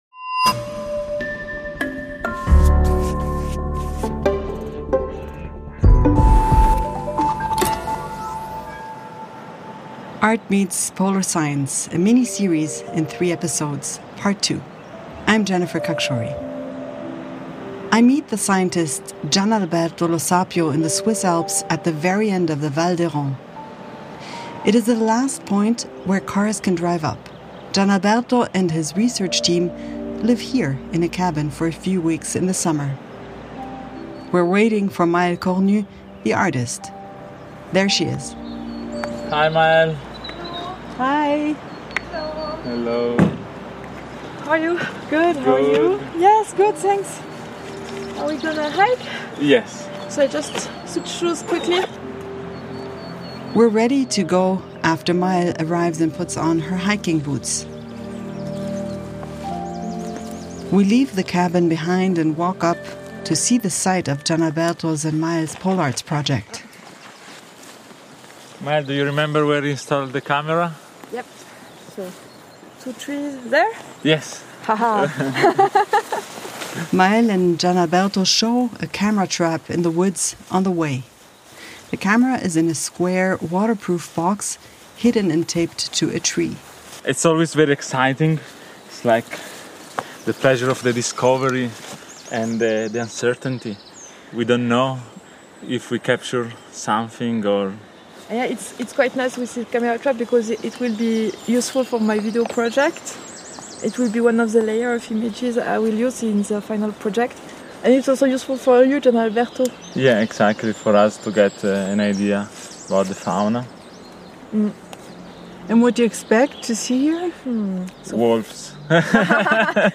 During a hike, the tandem discusses field work from a scientific and an artistic perspective and shares some of their joint activities such as the installation of camera traps to spot animals, the collection of samples and the use of microphotography.